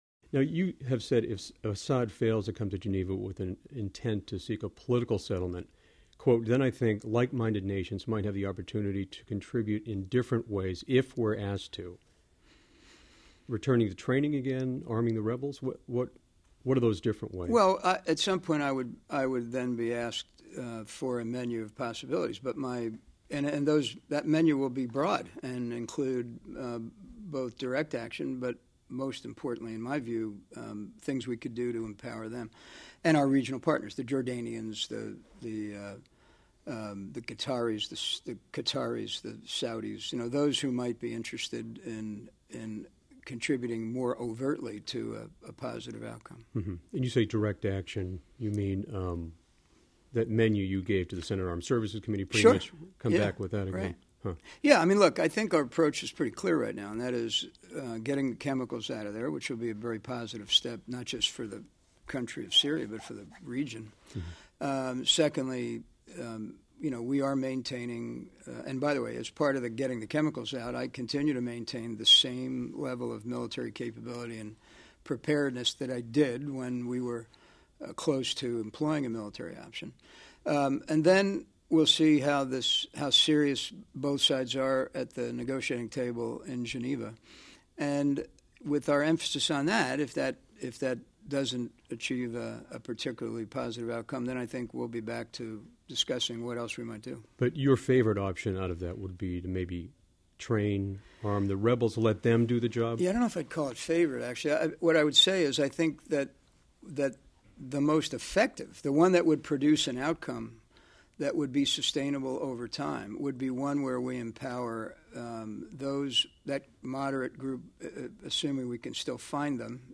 The chairman of the Joint Chiefs of Staff talks with NPR about why it's often better to advise and assist than to get involved militarily. And he looks at the Pentagon's looming budget crisis.